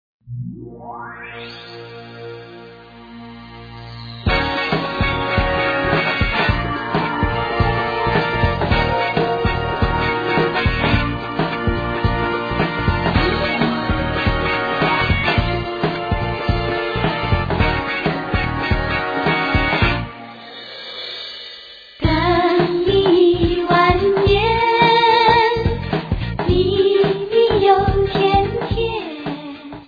three professionally trained violinists
Chinese popular music of the 20's